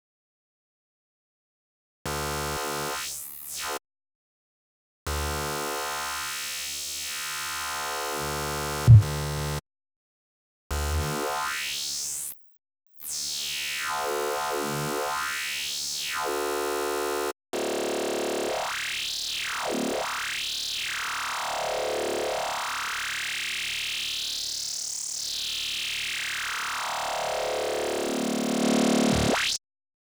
TOO-CLASSIC-Fm-Lead.wav